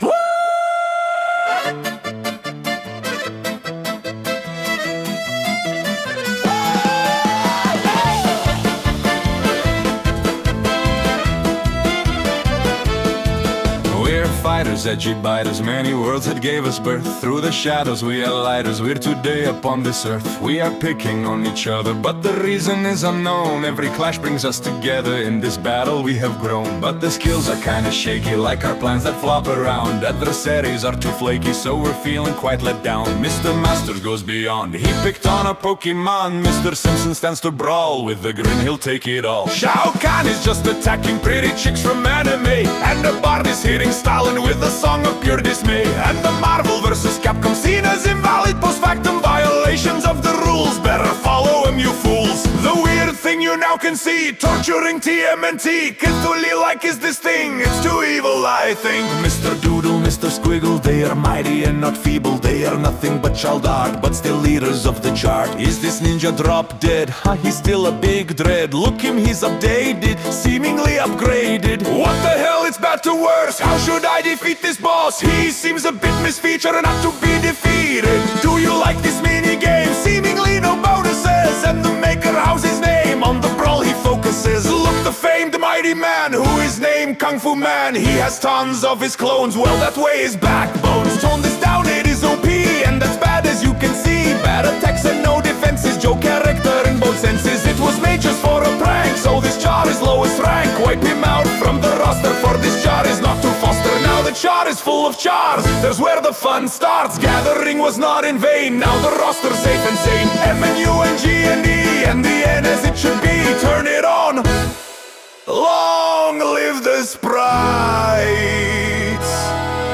English version: Ditties about MUGEN